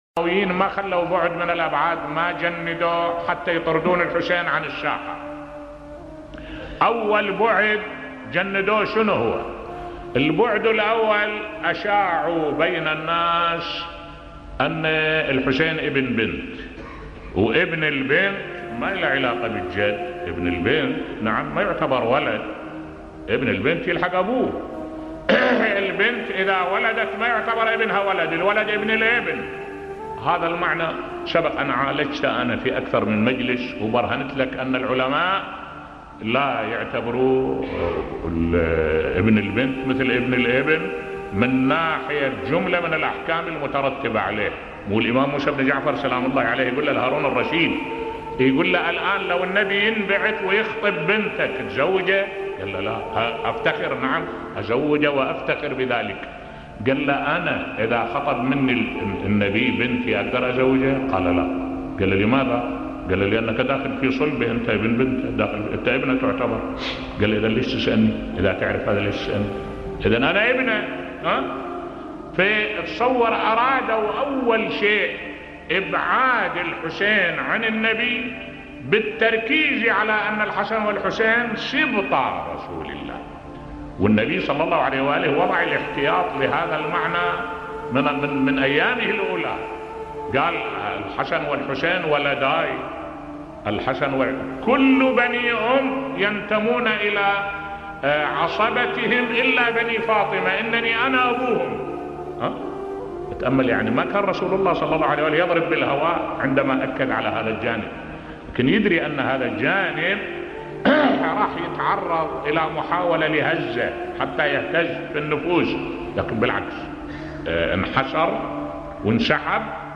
ملف صوتی تأكيد الرسول على نسب الحسن و الحسين بصوت الشيخ الدكتور أحمد الوائلي